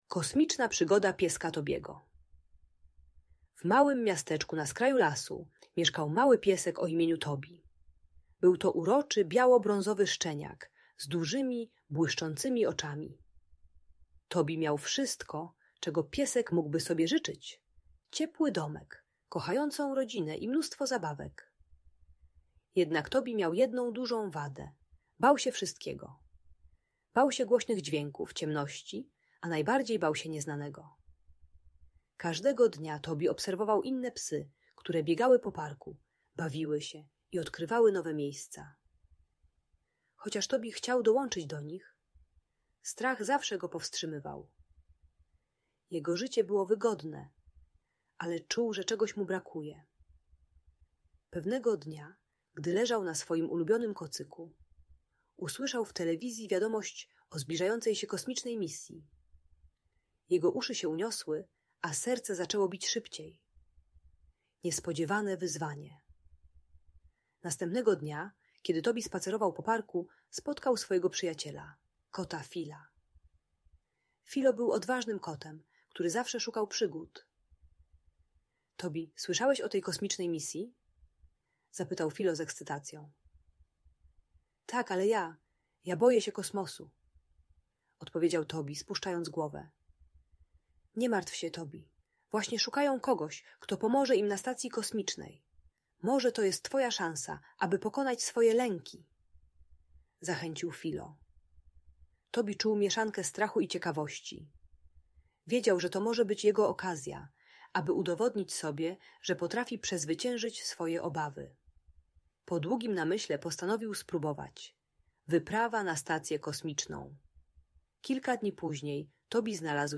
Kosmiczna Przygoda Tobiego - Historia o Odwadze i Przyjaźni - Audiobajka